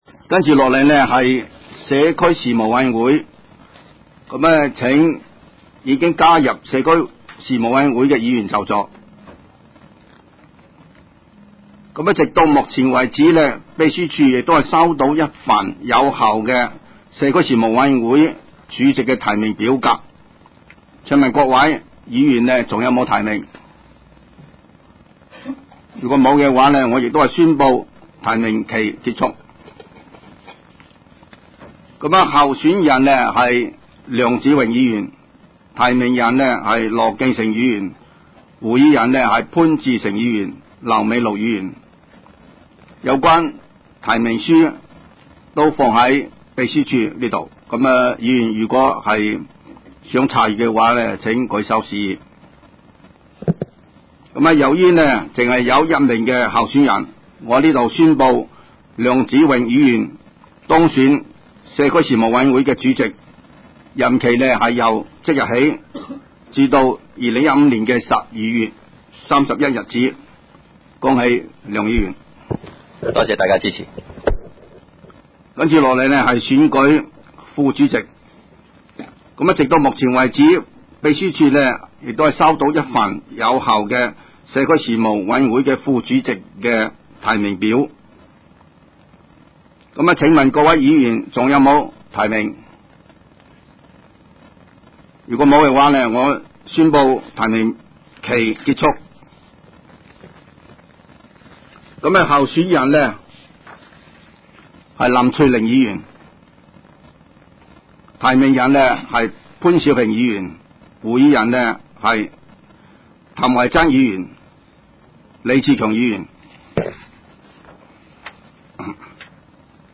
委员会会议的录音记录
地点: 香港葵涌兴芳路166-174号 葵兴政府合署10楼 葵青民政事务处会议室